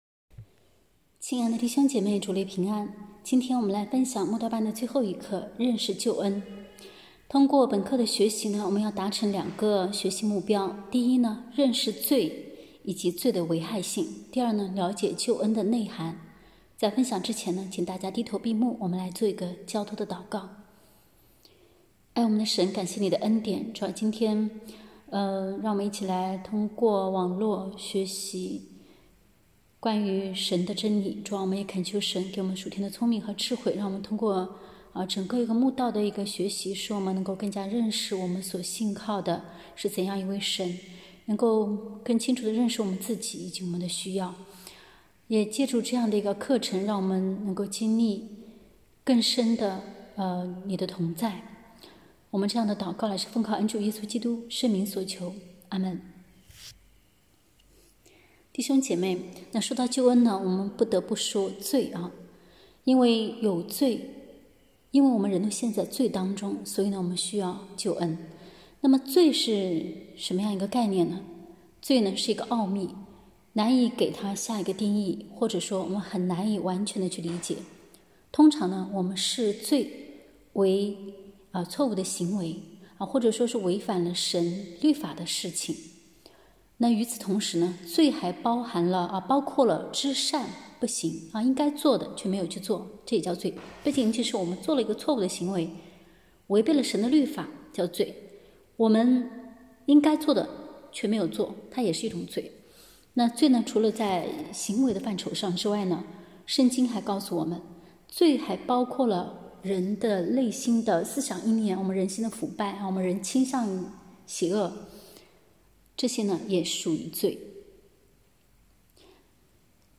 慕道班课程（八）